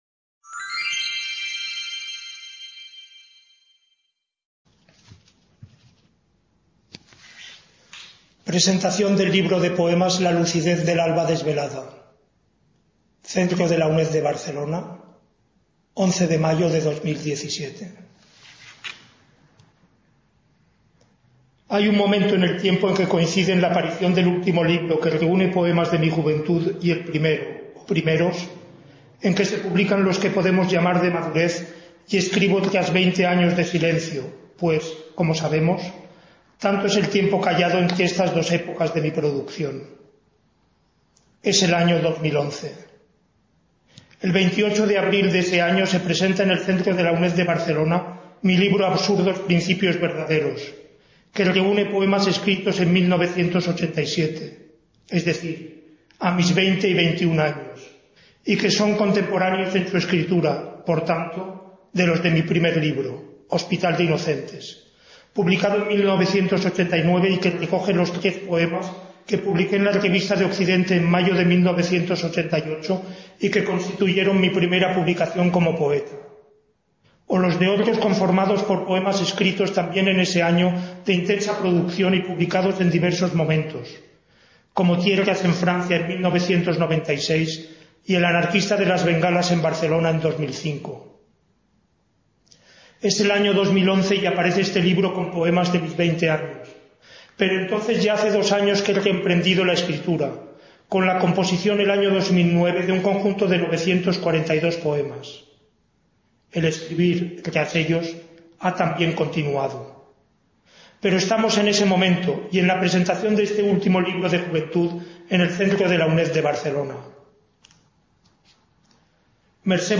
Presentación del libro de poemas La lucidez del alba…